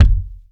Object Deep Thud
impact_deep_thud_bounce_09.wav